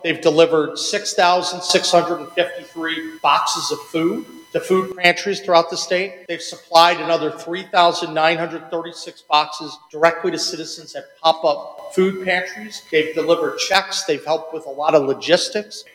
West Virginia Governor Patrick Morrisey will call the legislature to session if the need for more food bank funding becomes apparent. The governor held a press conference Tuesday to give an update on state efforts to fill the gap of missing SNAP benefits for 275,000 West Virginia residents.